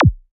Here You can listen to some of the Kicks included in the sample library:
• Versatile and Dynamic: Whether you're producing trance, psytrance, or any other high-energy electronic music, these kicks will add that essential punch to your tracks.
KICK-VIII-186.wav